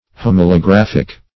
Homalographic \Hom`a*lo*graph"ic\, a.
homalographic.mp3